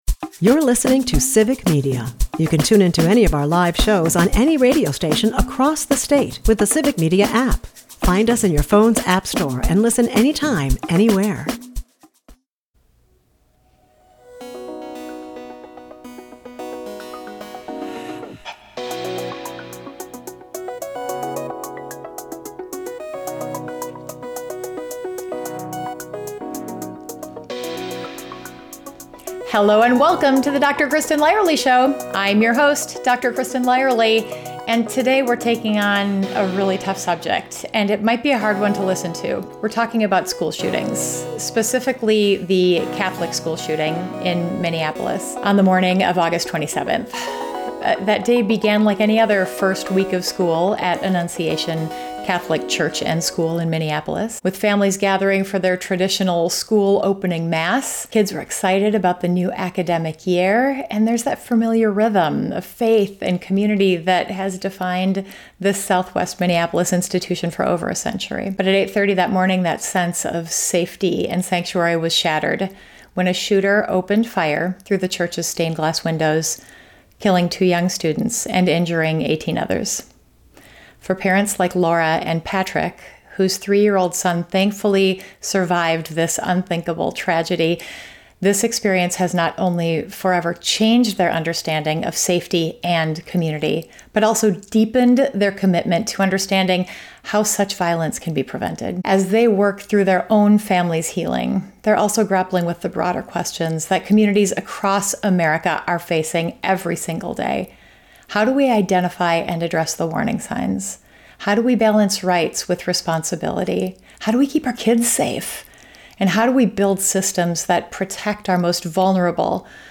In this raw and honest conversation, they share their story of that horrific morning - the chaos, the fear, and the immediate aftermath that no parent should ever have to endure.